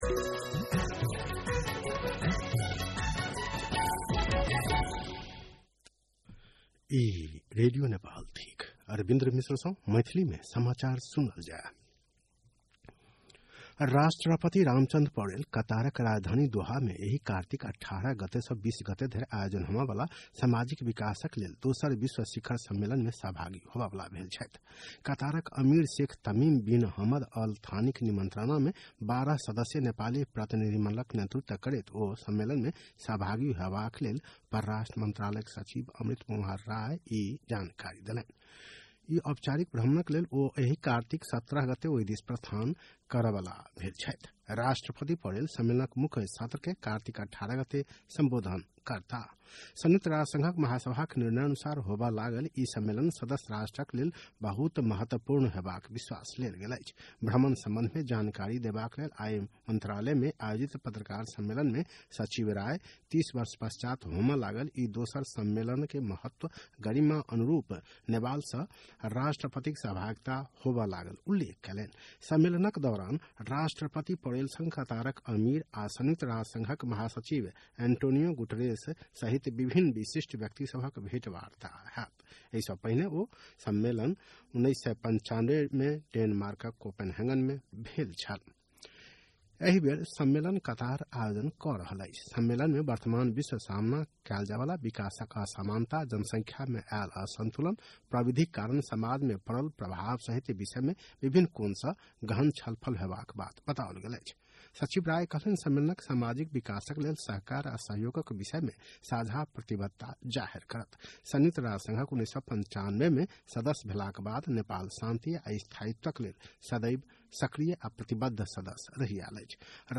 मैथिली भाषामा समाचार : १४ कार्तिक , २०८२
6-.-pm-maithali-news-.mp3